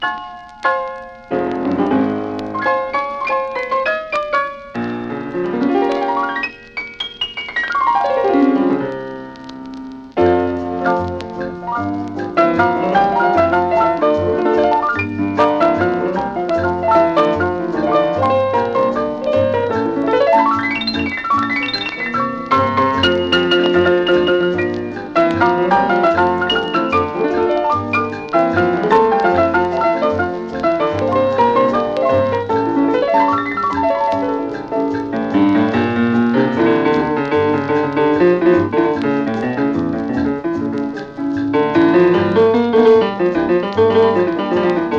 World, Latin, Jazz　USA　12inchレコード　33rpm　Mono
盤擦れキズ　プチプチノイズ